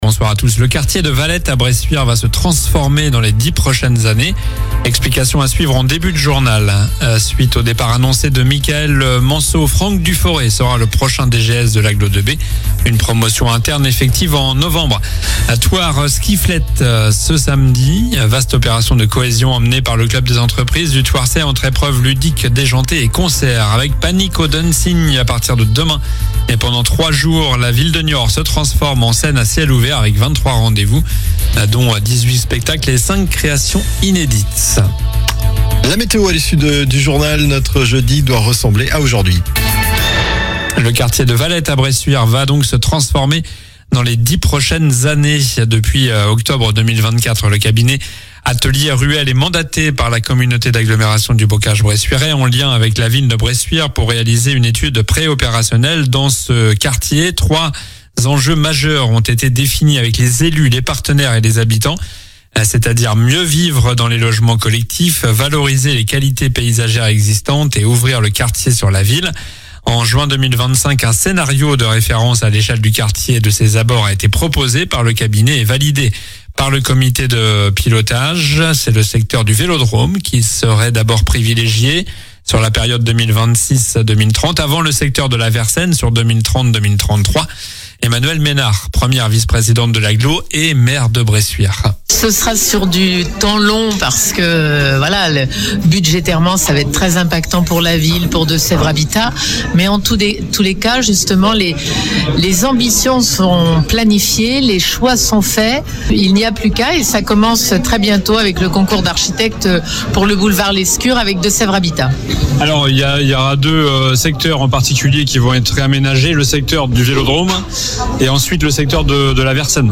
COLLINES LA RADIO : Réécoutez les flash infos et les différentes chroniques de votre radio⬦
Journal du mercredi 24 septembre (soir)